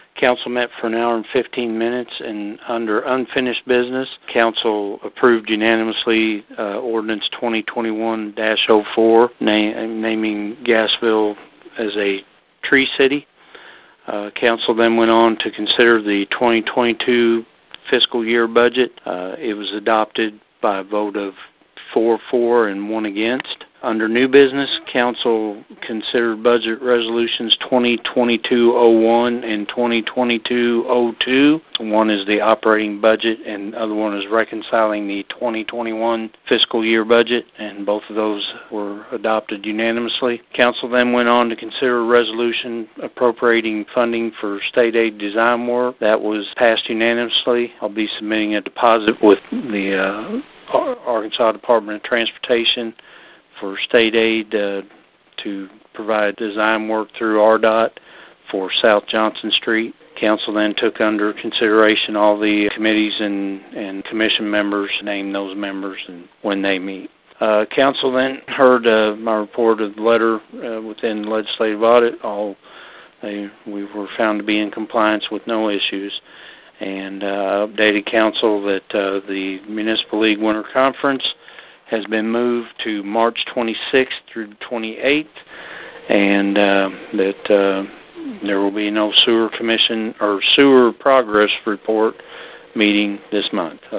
The Gassville City Council met in regular session Tuesday evening, with the 2022 fiscal year budget among the agenda items. Mayor Jeff Braim has the report.